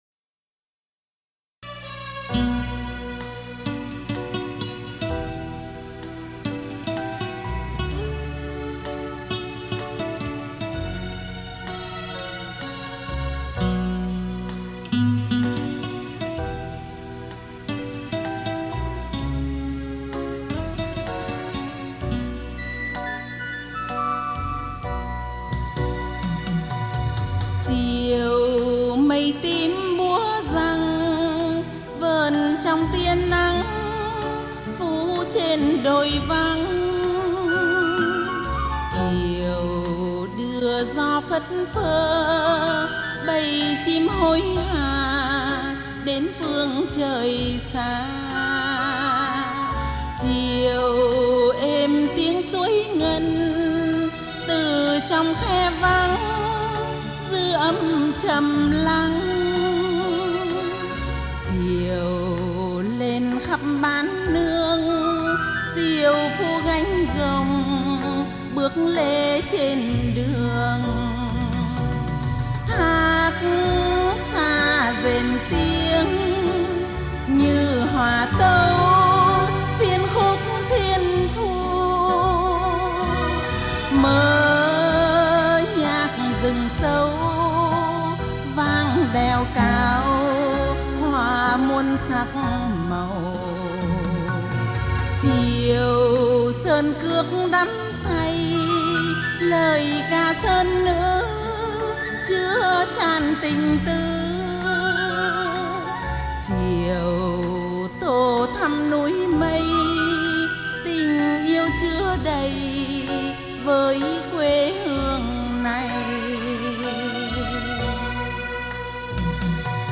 Tù Khúc